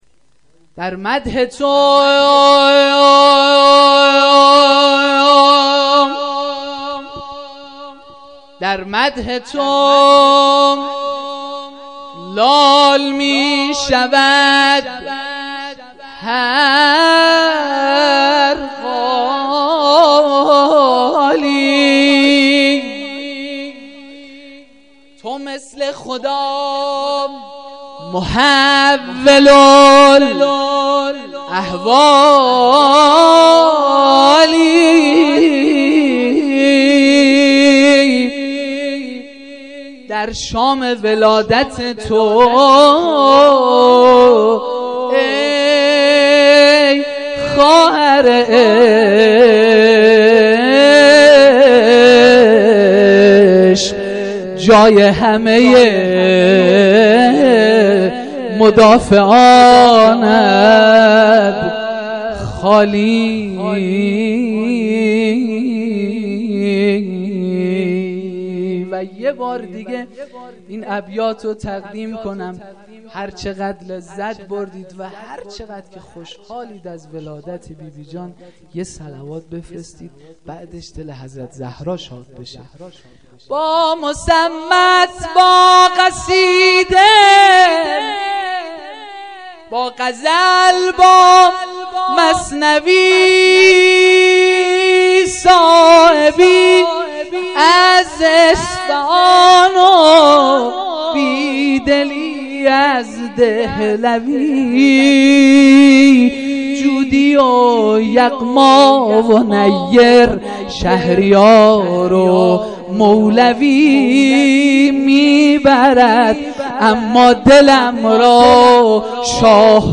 جلسه مذهبی زیارت آل یاسین باغشهر اسلامیه
جشن میلاد حضرت زینب (س)، زینبیه اسلامیه، جمعه ۹۵/۱۱/۱۵